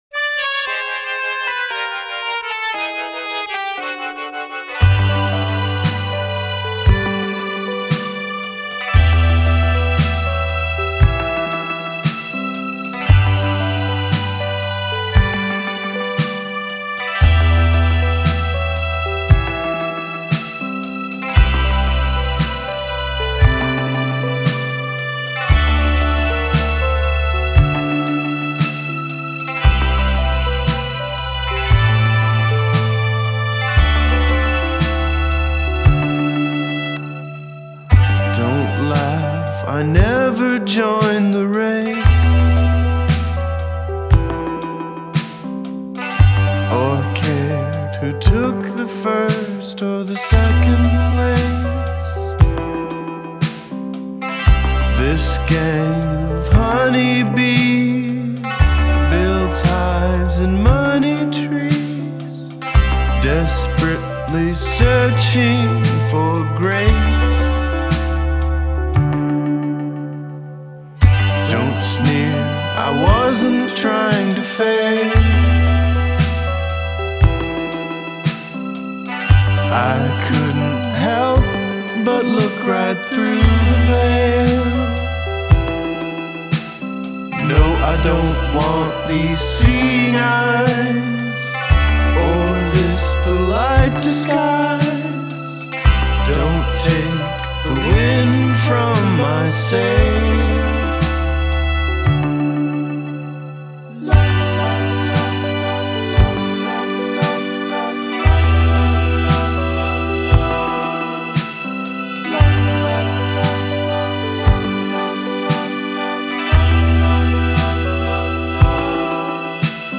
ambling vibe, classic rock undertones